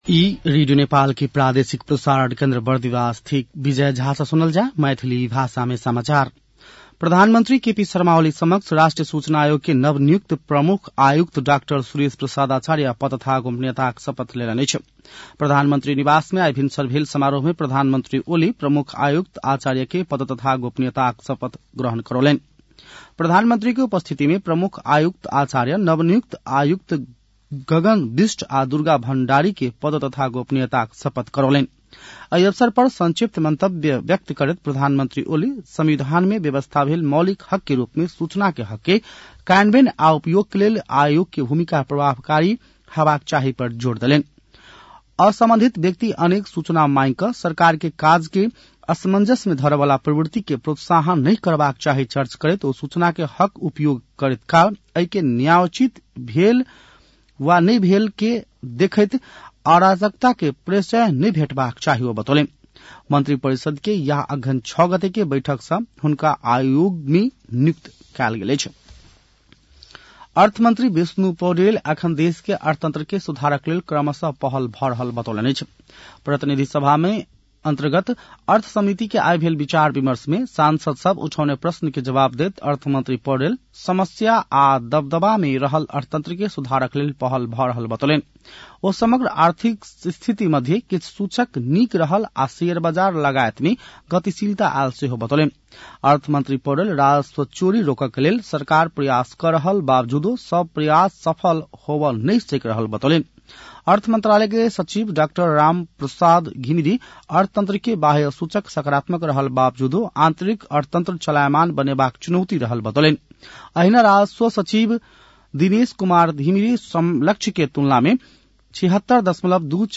An online outlet of Nepal's national radio broadcaster
मैथिली भाषामा समाचार : २७ मंसिर , २०८१